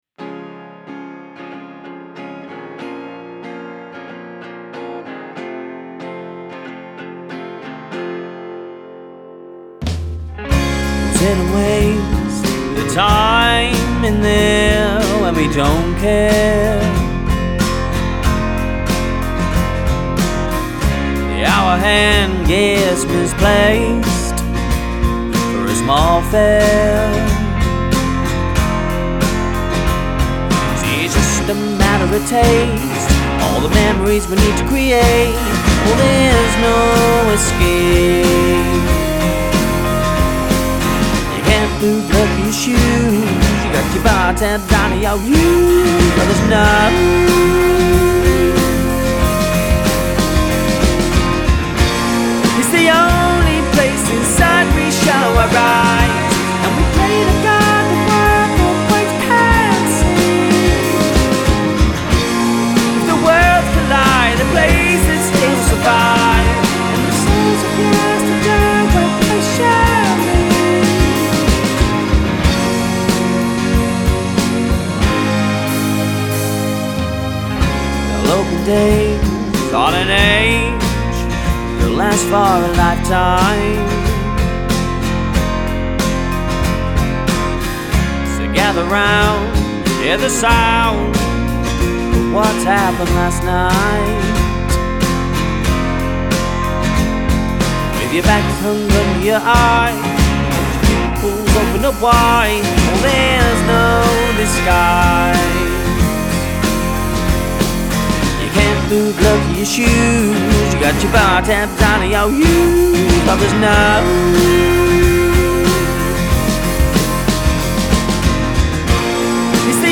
Whether as a solo acoustic artist or with his band
A striking singer-songwriter with falsetto vocals ."